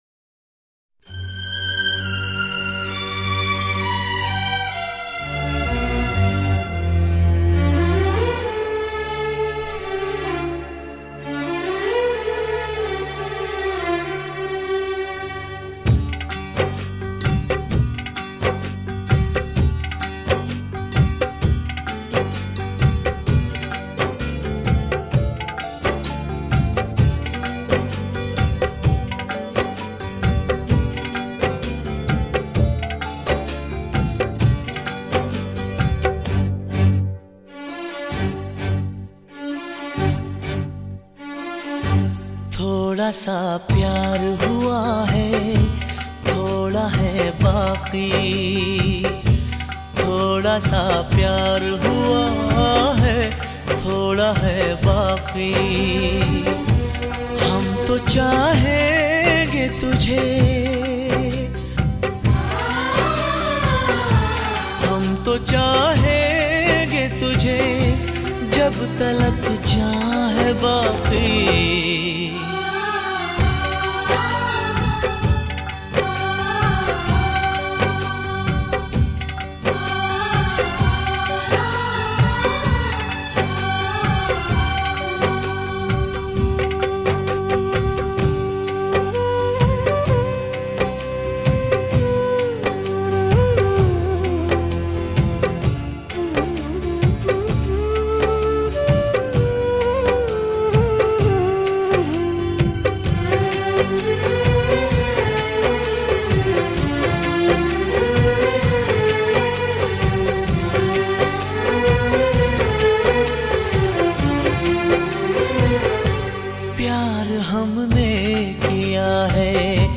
Bollywood track